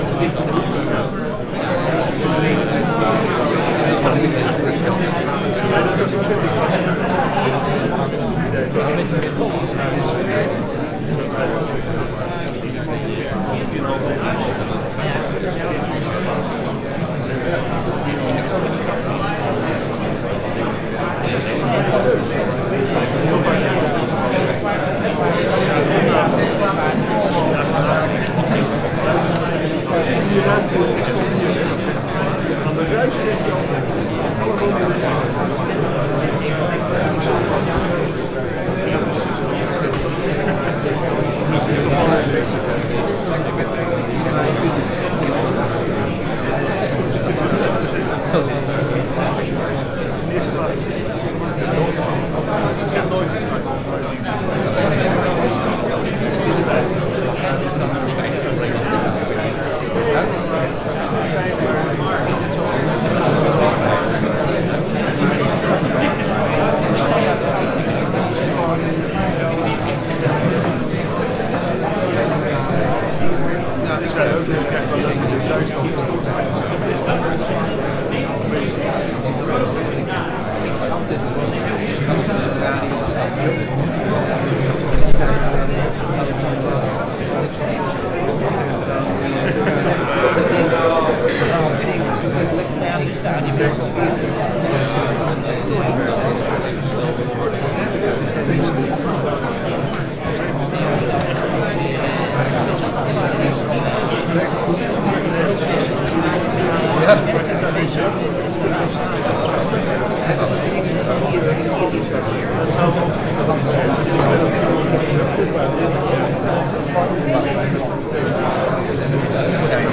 Meestal is het achtergrond geluid ook sterk gestructureerd.
title('Babble noise')
babble.wav